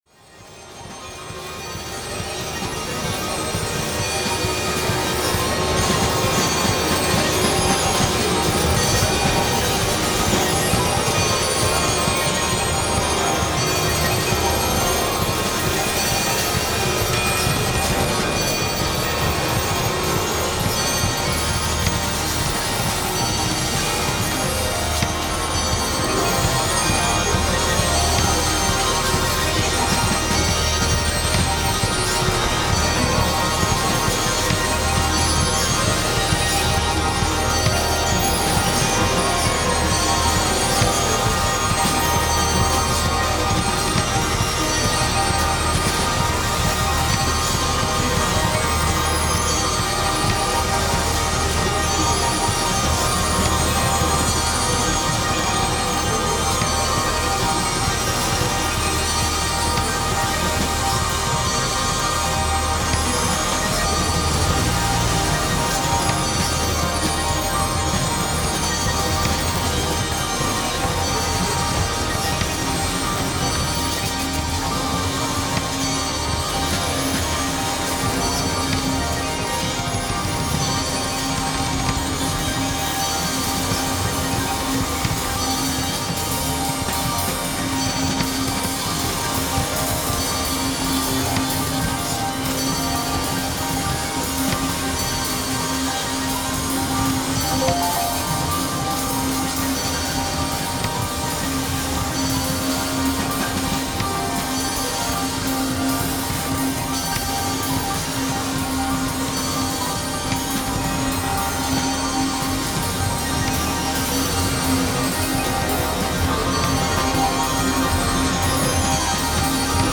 This is a podcast of recently shared mixes.
Electronic